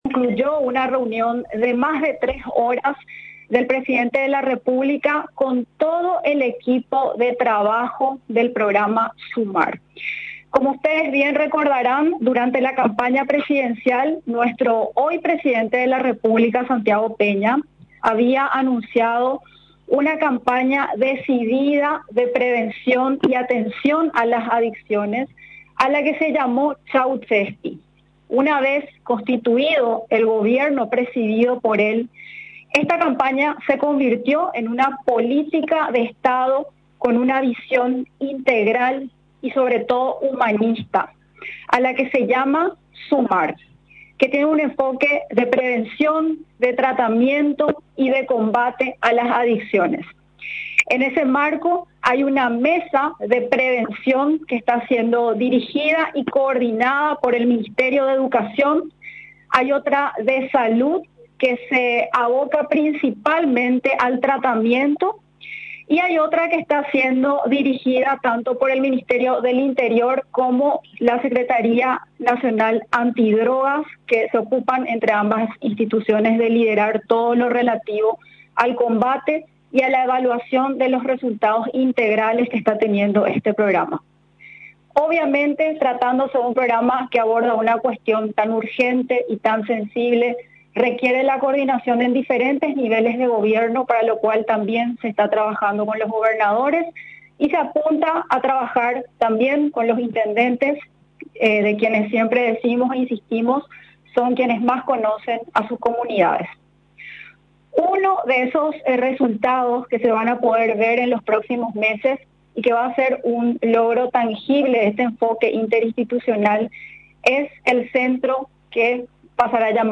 Durante la rueda de prensa, realizada en la Residencia Presidencial de Mburuvichá Róga, el secretario de Estado, explicó que el objetivo de la institución a su cargo, no es el abordaje de un individuo, sino del individuo dentro de la comunidad.